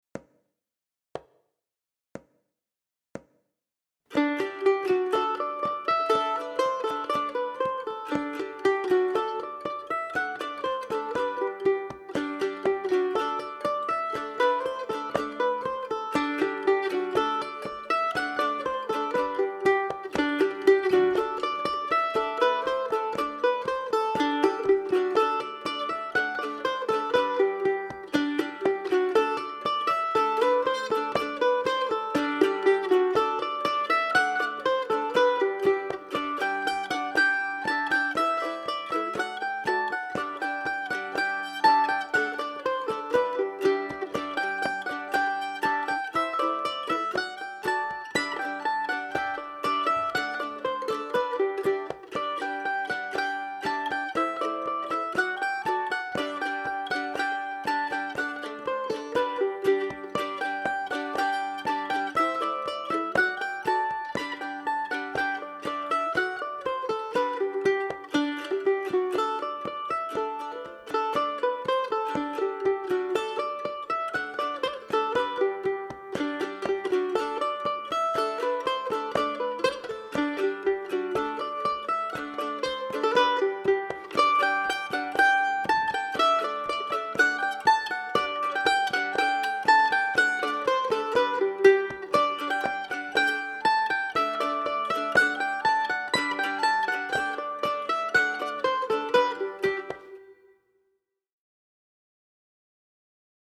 DIGITAL SHEET MUSIC - MANDOLIN SOLO
Celtic/Irish, Mandolin Solo